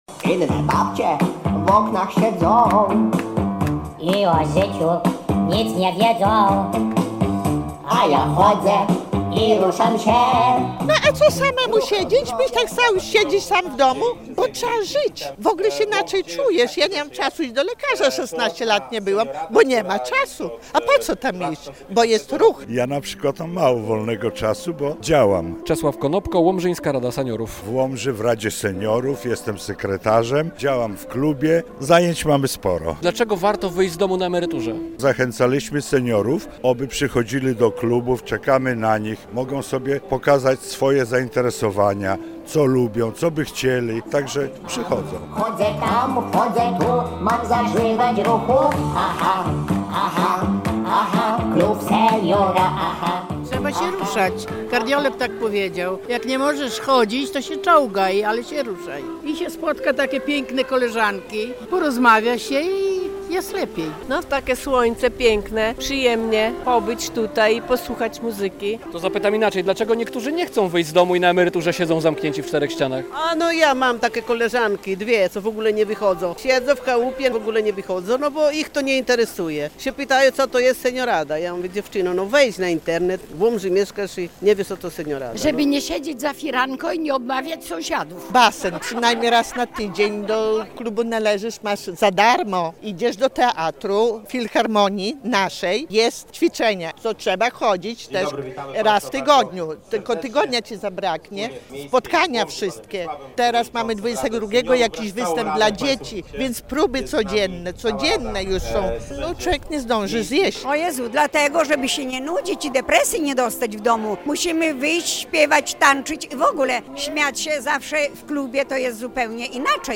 Na Starym Rynku w Łomży spotkało się kilkuset seniorów
relacja